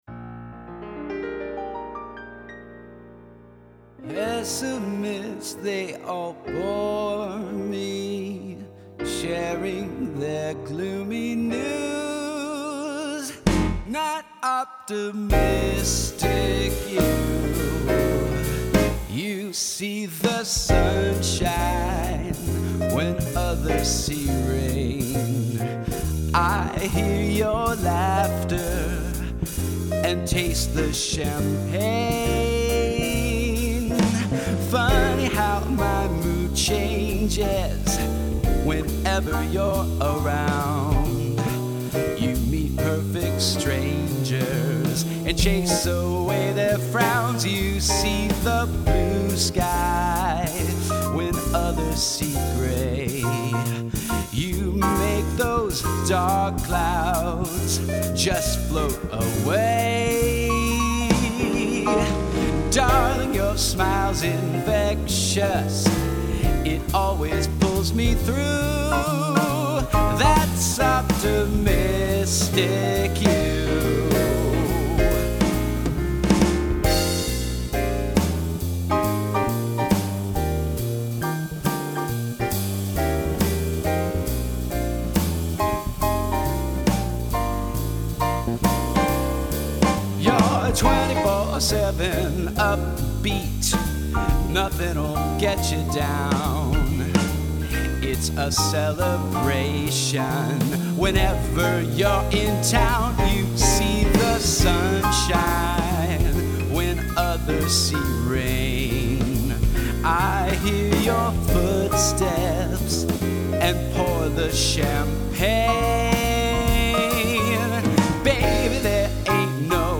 Hop aboard the F Train for this grand, old-school, Broadway-style musical comedy being presented for one performance only in a concert reading at Catskill’s Bridge Street Theatre on Sunday May 21 at 2:00pm.